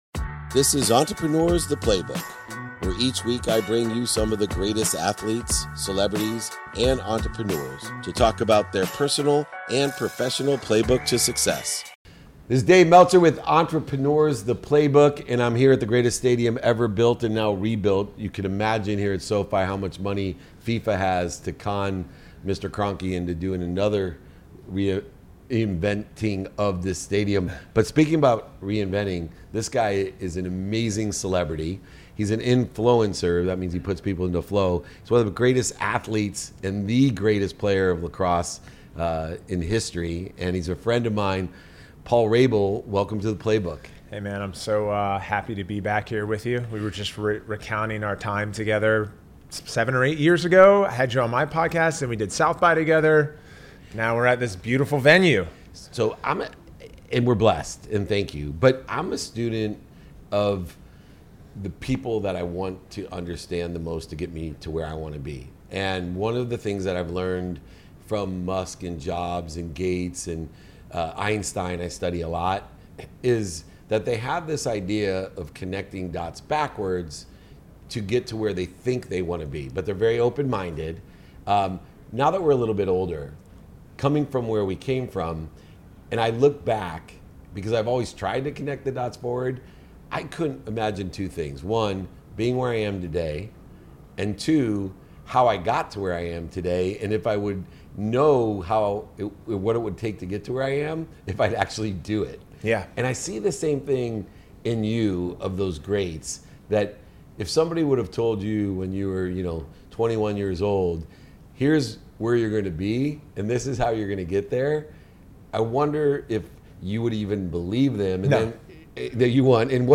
In today's episode, I'm joined by Paul Rabil, an American sports executive and retired professional lacrosse player, widely recognized as one of the greatest in the sport.